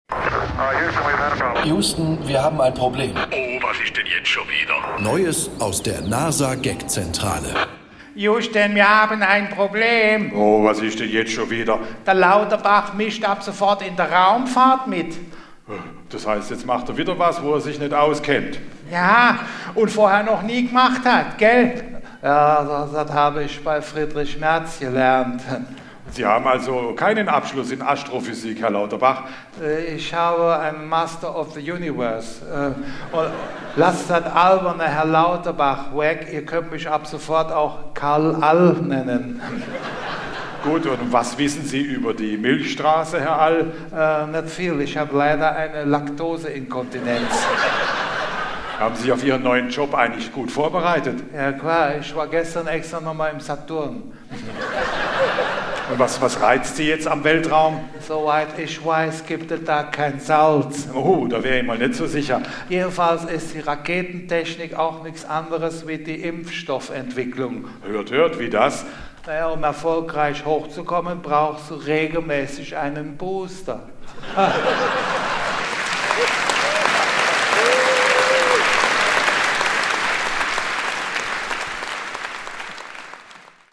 Aber der kam erst später – von Andreas Müller, live gespielt gestern Abend in Müllheim...